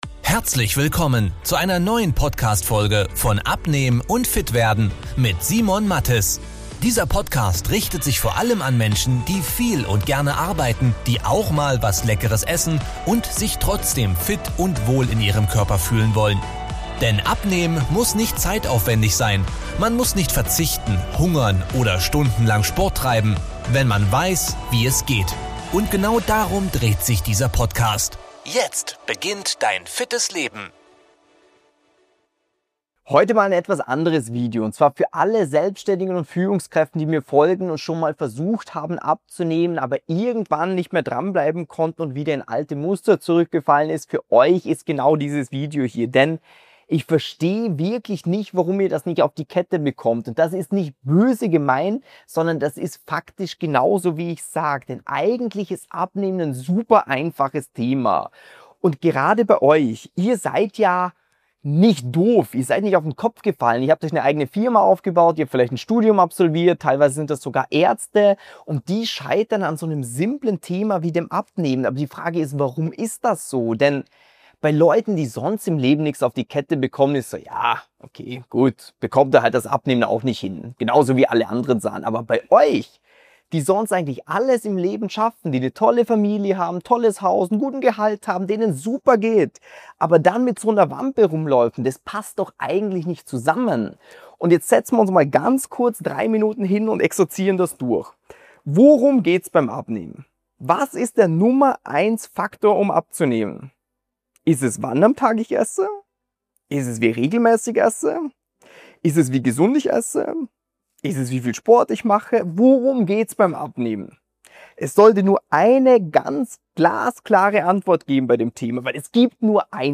#382 Schnell und nachhaltig abnehmen, ohne Verzicht! - Kundeninterview